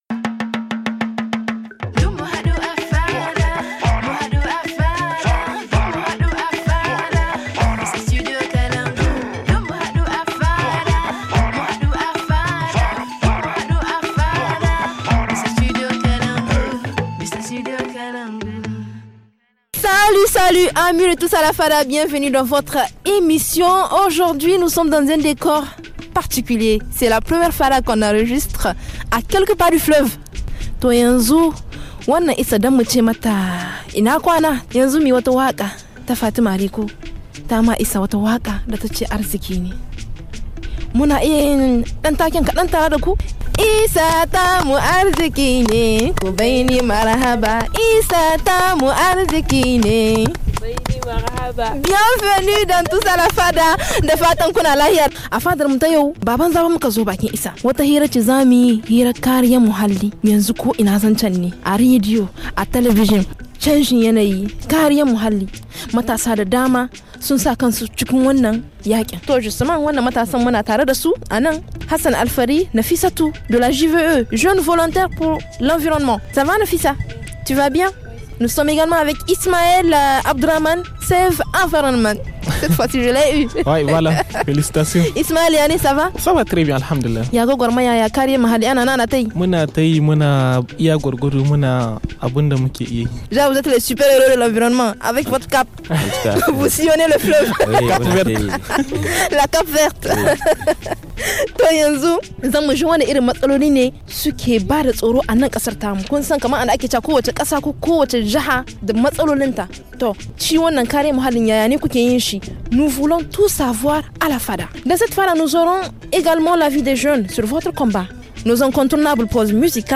Aujourd’hui la Fada est posée dans un coin spécial, au bord du fleuve.